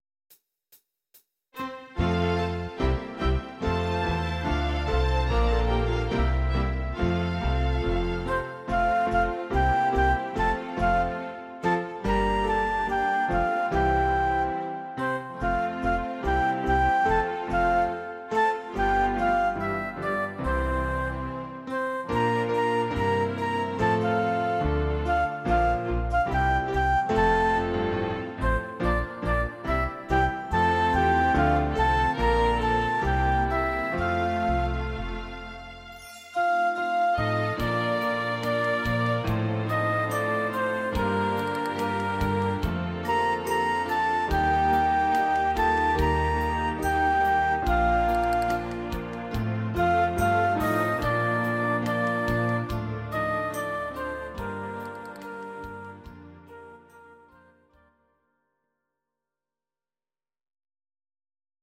These are MP3 versions of our MIDI file catalogue.
Please note: no vocals and no karaoke included.
modernes Kinderlied